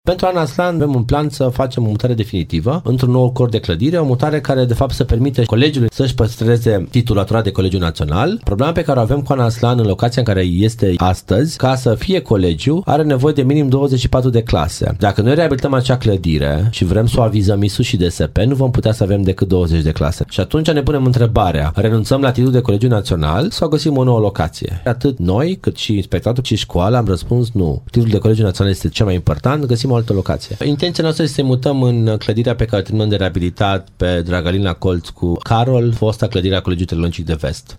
Viceprimarul Ruben Lațcău spune că elevii nu vor mai reveni în clădirea de pe bd. Revoluției la finalizarea lucrărilor.